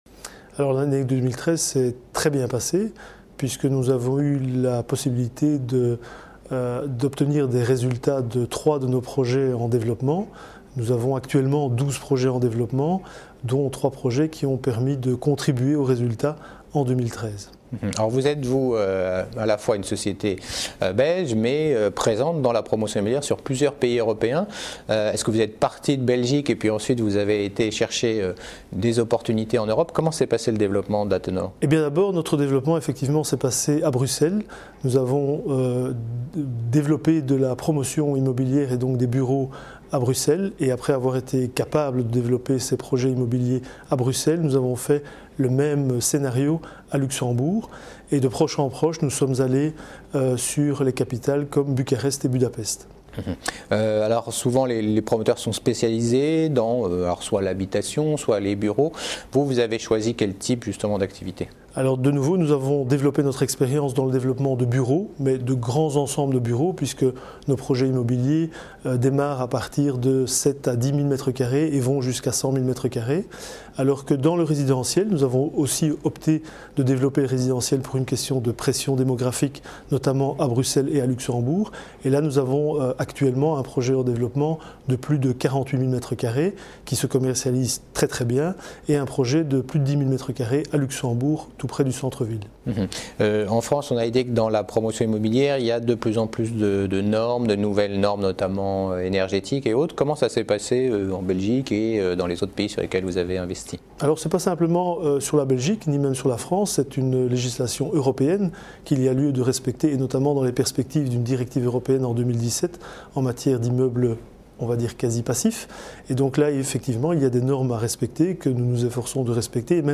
Rencontre à l'European Small Cap Event du promoteur immobilier
La Web Tv partenaire de l’European Small Cap Event organisé par CF&B Communicaiton qui s’est tenu à Paris le 7 et le 8 avril.